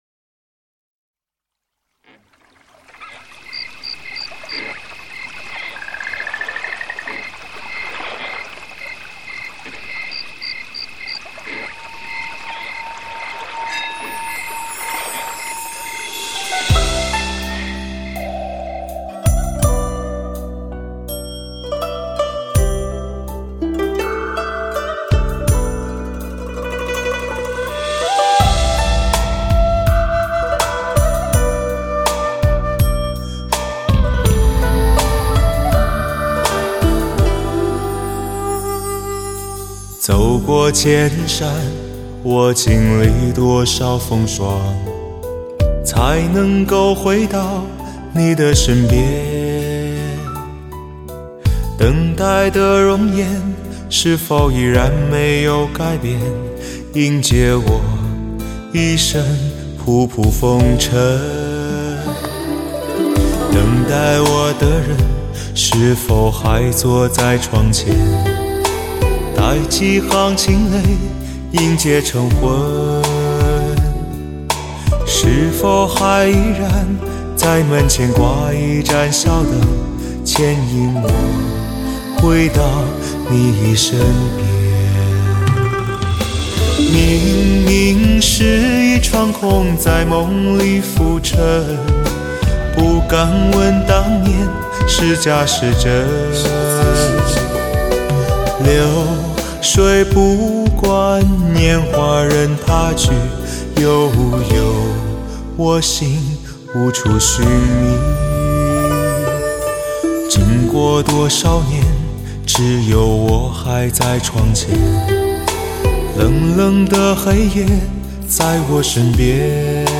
磁性动人
HQCD达到原始母带完美音质的再现。